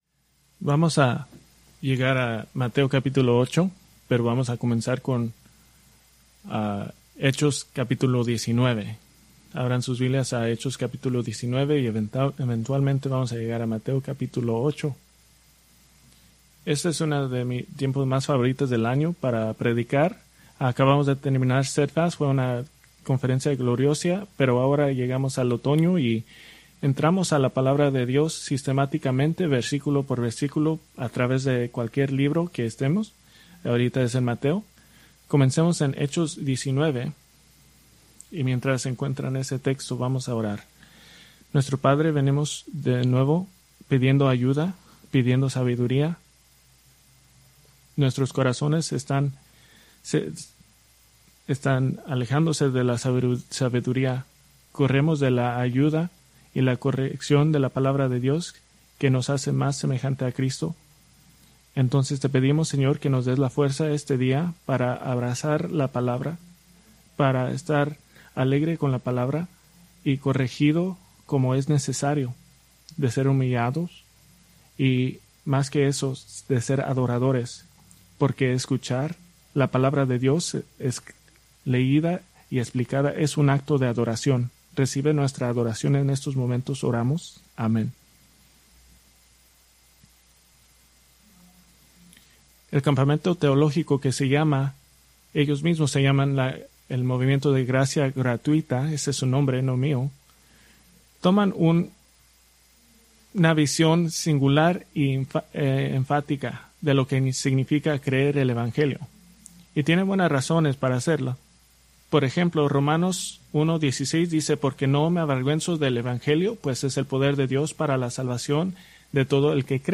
Preached October 6, 2024 from Mateo 8:18-22